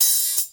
Open Hat
Original creative-commons licensed sounds for DJ's and music producers, recorded with high quality studio microphones.
80s-crisp-open-hat-sound-g-sharp-key-54-xl6.wav